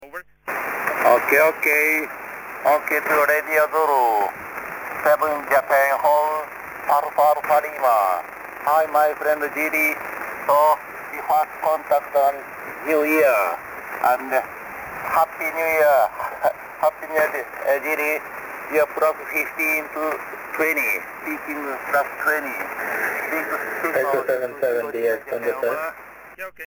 Jak posloucháte DXy v pásmu 80m?